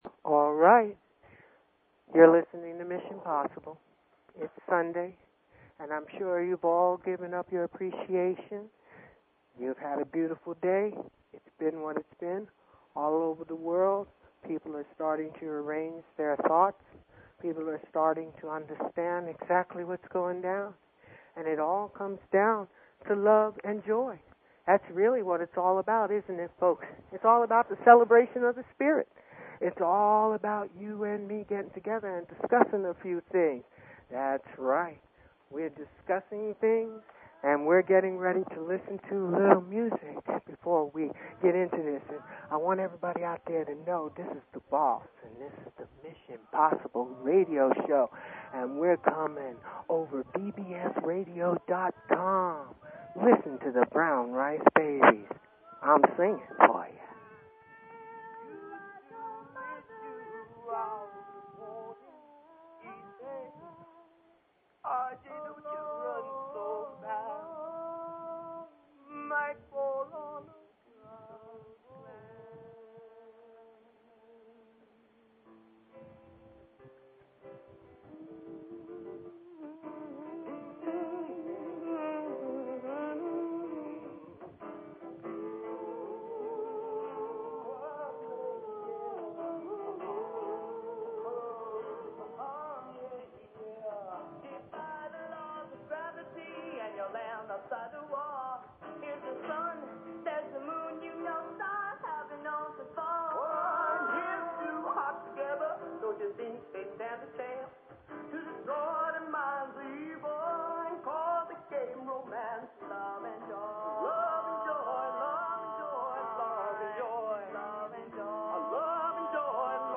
Talk Show Episode, Audio Podcast, Mission_Possible and Courtesy of BBS Radio on , show guests , about , categorized as
This show is about the Spirit. Interview